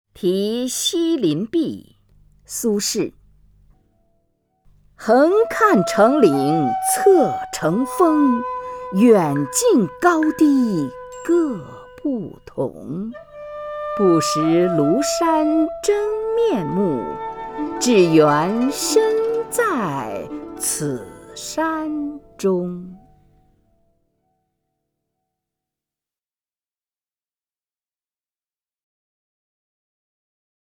虹云朗诵：《题西林壁》(（北宋）苏轼) （北宋）苏轼 名家朗诵欣赏虹云 语文PLUS